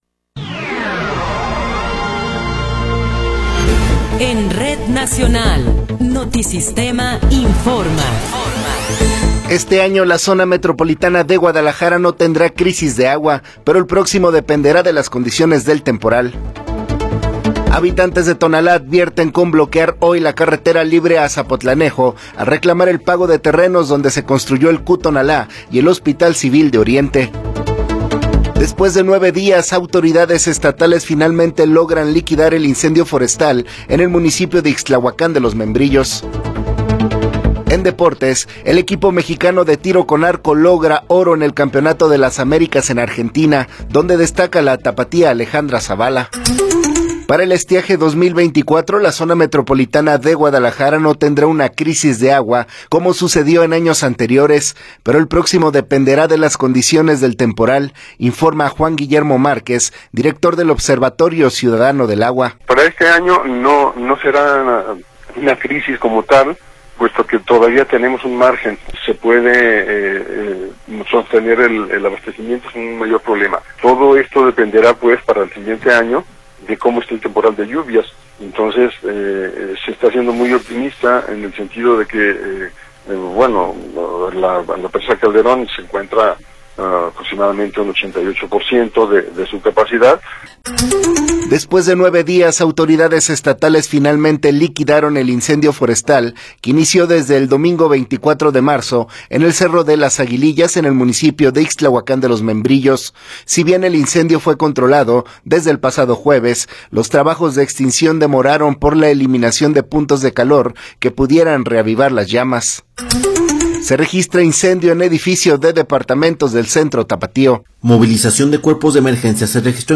Noticiero 9 hrs. – 3 de Abril de 2024